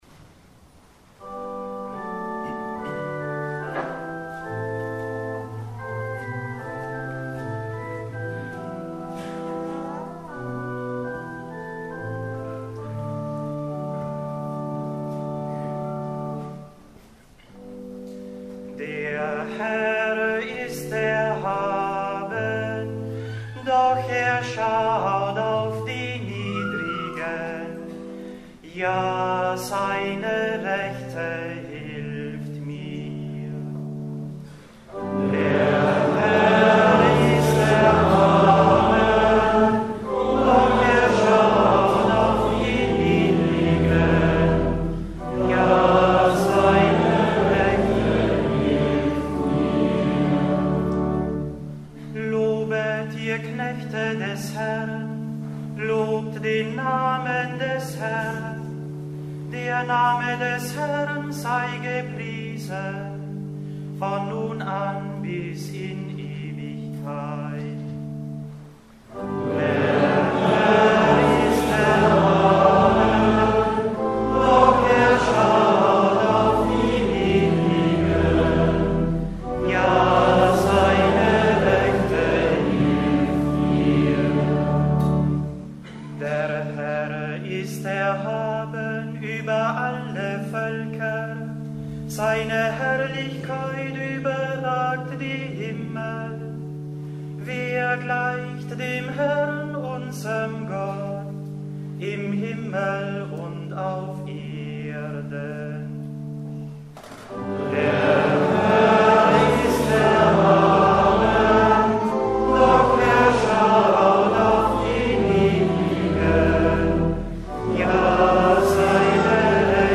100 Jahre Salesianer Don Boscos in Stadlau: Festmesse - Lieder